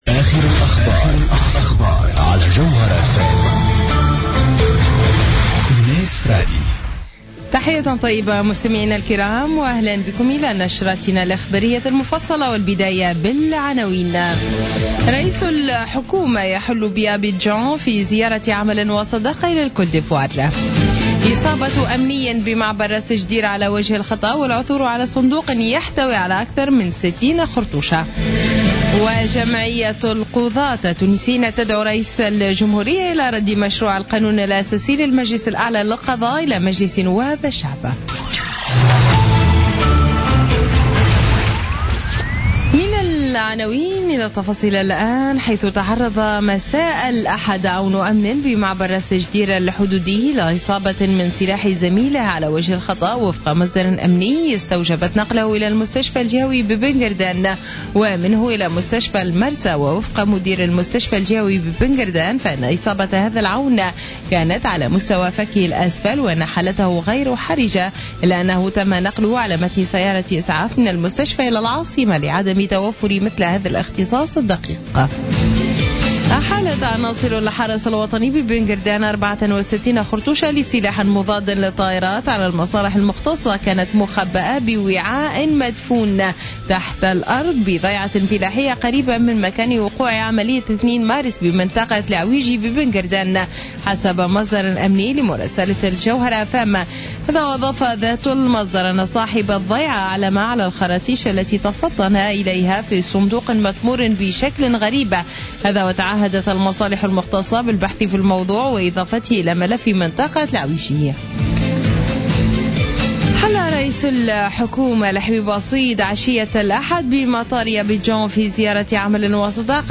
نشرة أخبار منتصف الليل ليوم الاثنين 25 أفريل 2016